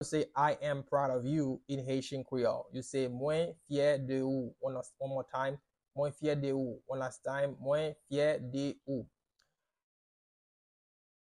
Pronunciation:
I-am-proud-of-you-in-Haitian-Creole-Mwen-fye-de-ou-pronunciation-by-a-Haitian-teacher.mp3